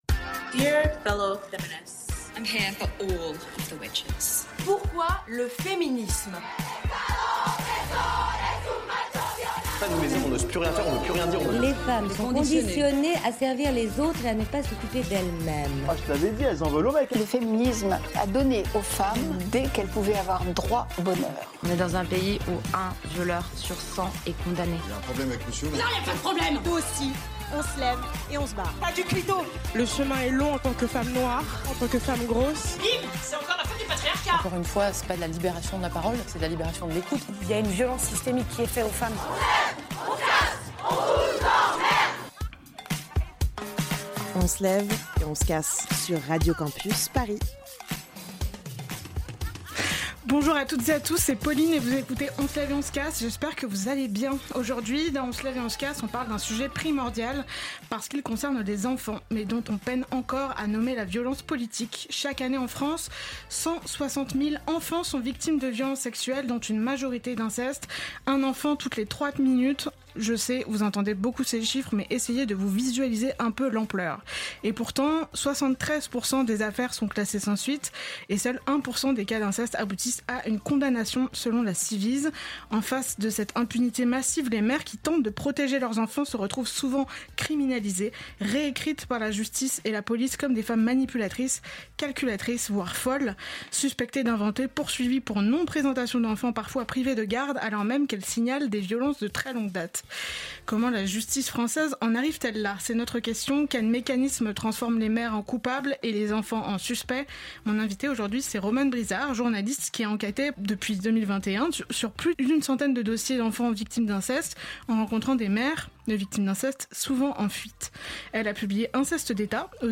Partager Type Magazine Société mardi 27 janvier 2026 Lire Pause Télécharger Chaque année en France, 160 000 enfants sont victimes de violences sexuelles, le plus souvent dans le cadre familial.